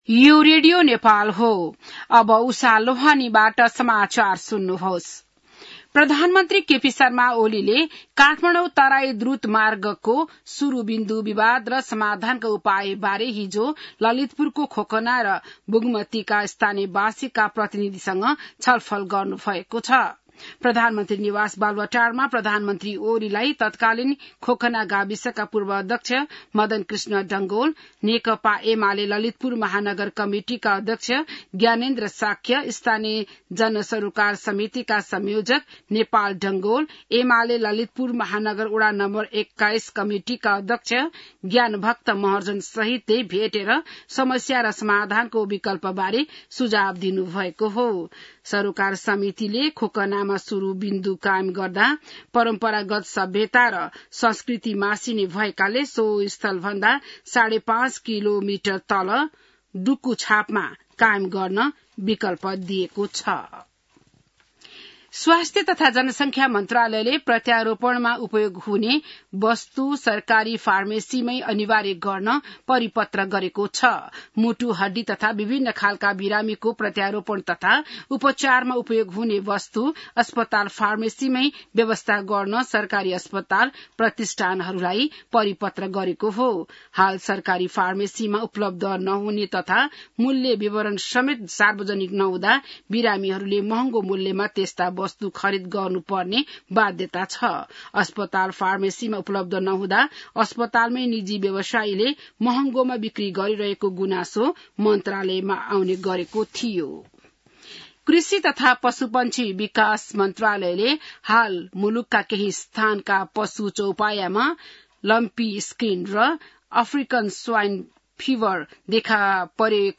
बिहान १० बजेको नेपाली समाचार : २७ जेठ , २०८२